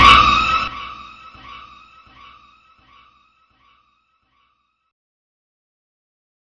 Scream 5.wav